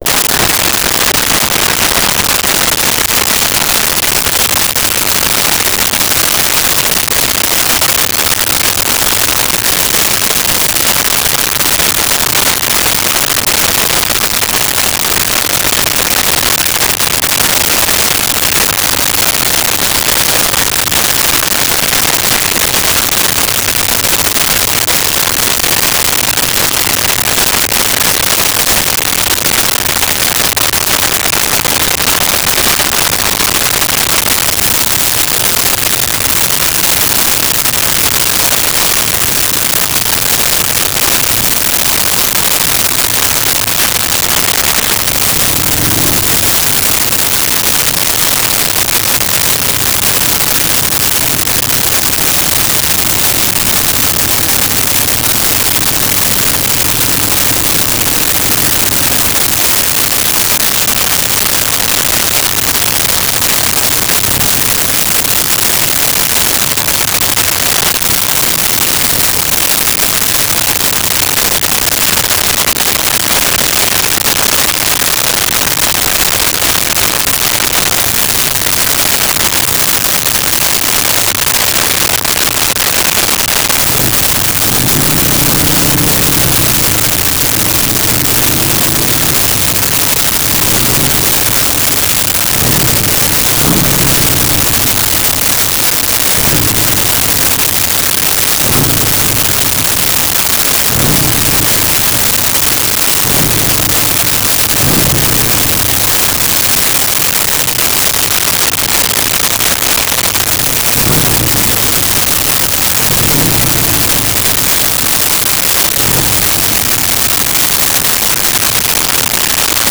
Motorcycle Start Idle Revs Off
Motorcycle Start Idle Revs Off.wav